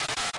格斗电子游戏配音
Tag: 电子游戏 冲击 击打 爆裂 拍击 格斗 狠击